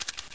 Звук из CS 1.6 — зум прицела снайперской винтовки